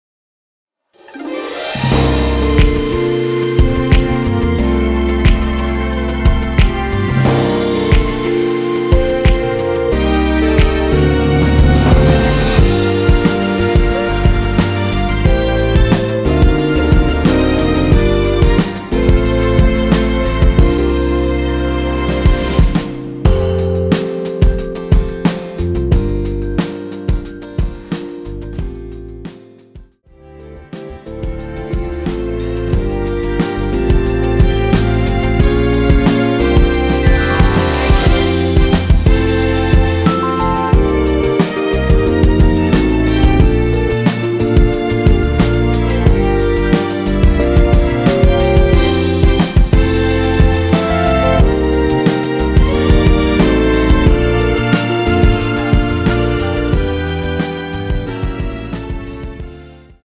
원키에서(+2)올린 (1절앞+후렴)으로 진행되는 MR입니다.
앞부분30초, 뒷부분30초씩 편집해서 올려 드리고 있습니다.
중간에 음이 끈어지고 다시 나오는 이유는